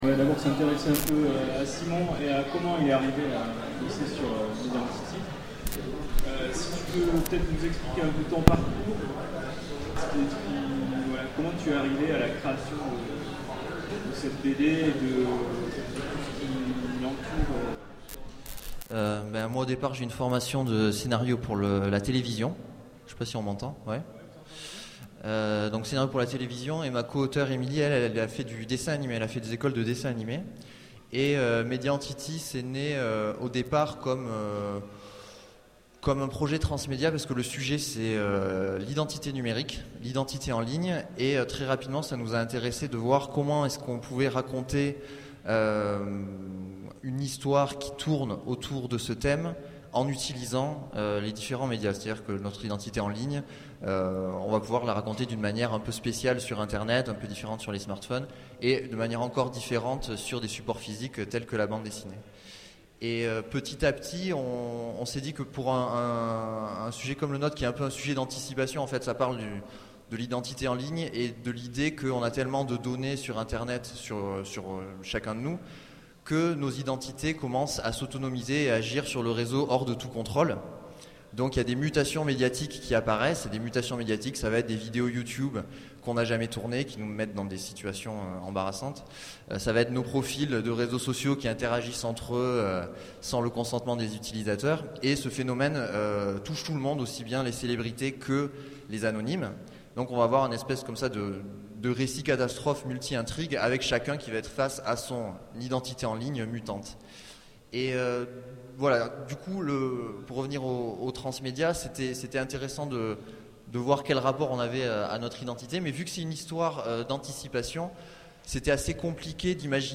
Utopiales 13 : Conférence Media Entity - ActuSF - Site sur l'actualité de l'imaginaire